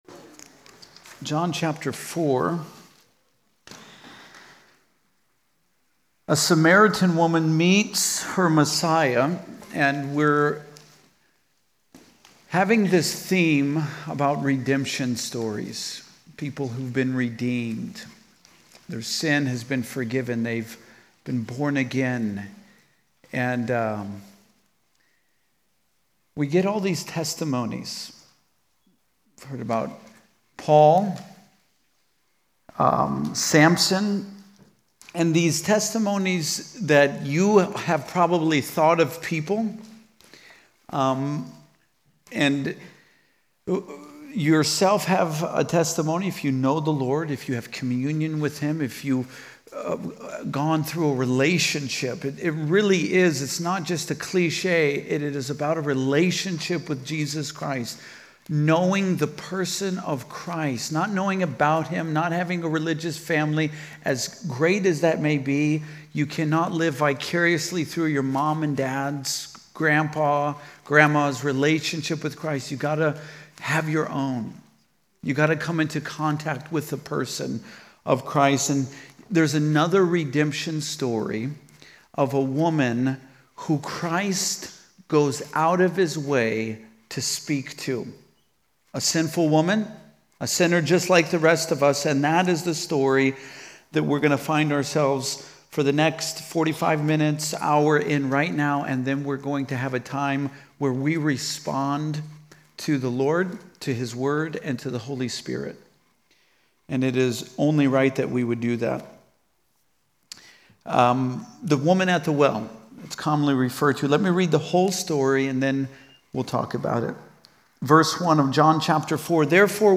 Home » Sermons » Redemption Stories: The Woman at the Well
Conference: Youth Conference